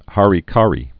(härē-kärē, hărē-kărē)